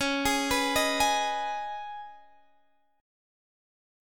C#7sus2 chord